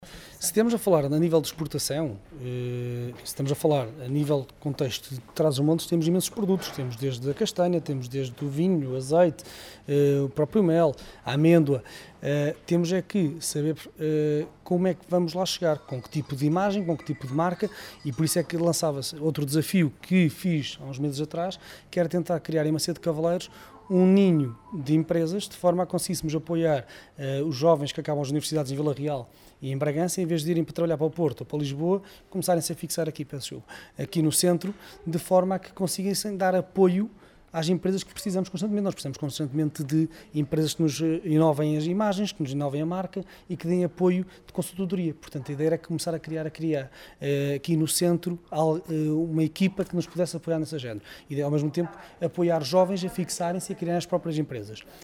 Declarações à margem da sessão de esclarecimento “Macau como Plataforma dos 3 Centros – Apoio à exportação para o mercado chinês”, que ontem decorreu em Macedo de Cavaleiros.